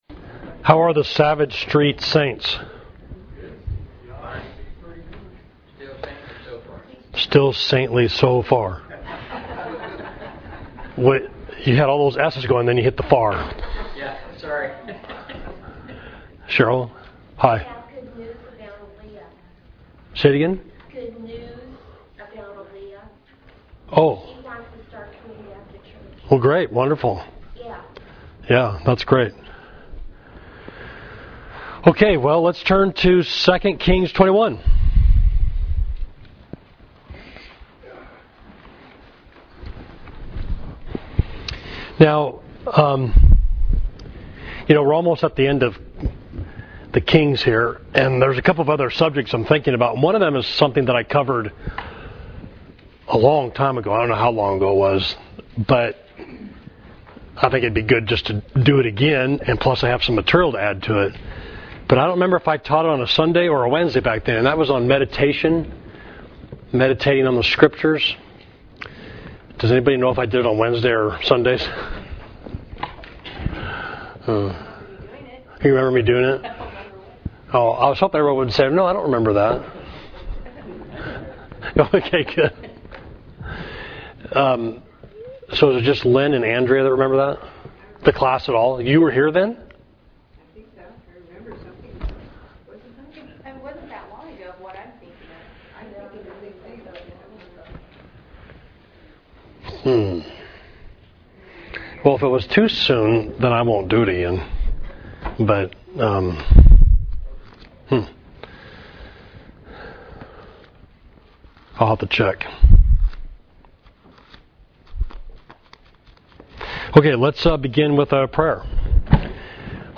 Class: Manasseh and Amon, 2 Kings 21 – Savage Street Church of Christ